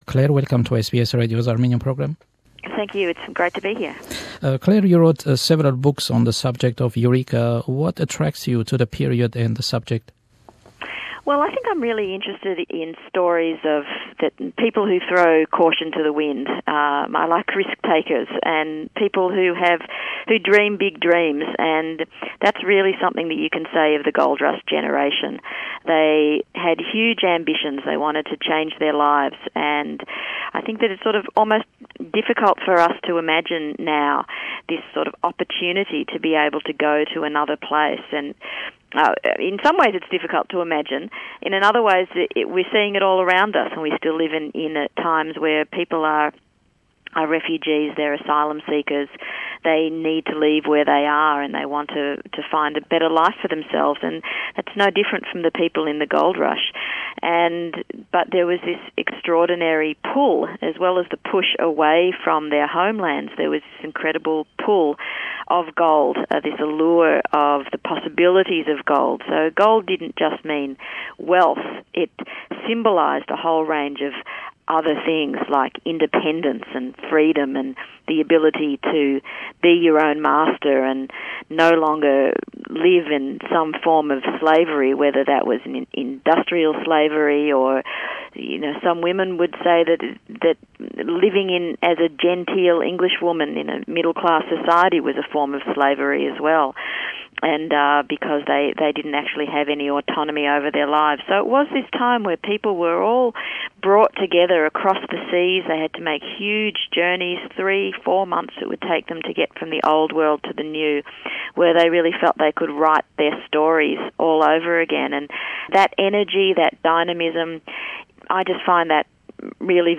Our guest is historian, award winning author and academic, Dr Clare Wright.